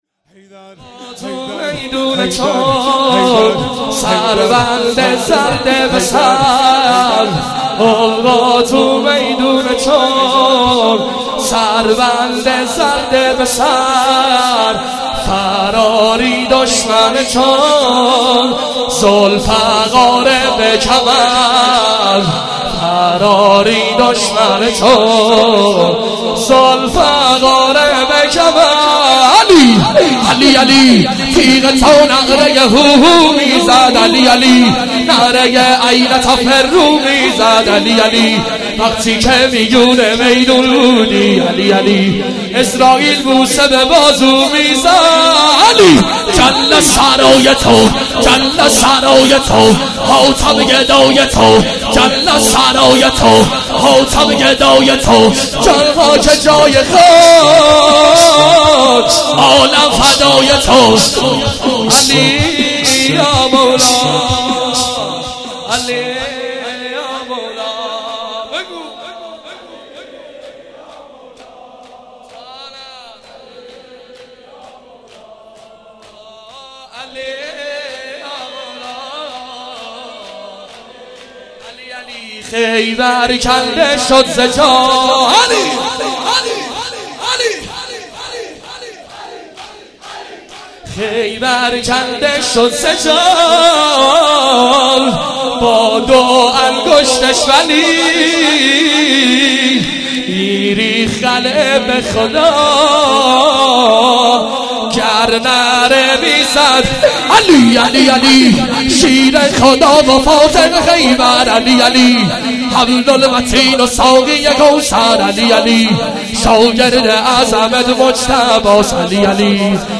چهاراه شهید شیرودی حسینیه حضرت زینب (سلام الله علیها)
شور- سقا کاری کن علی اصغر بی تابه